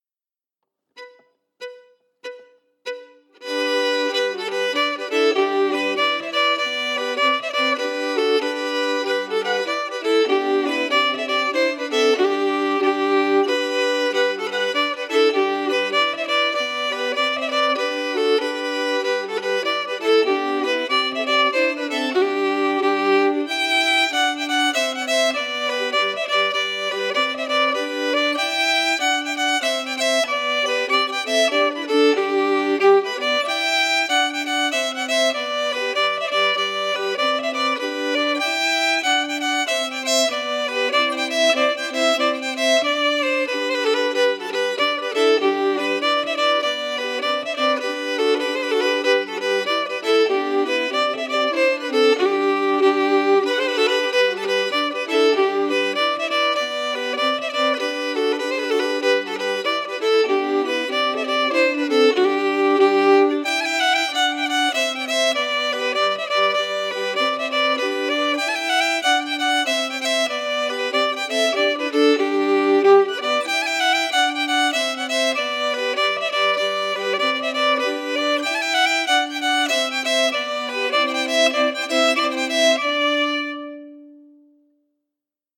Key: G
Form: Reel
Melody emphasis
Region: Ireland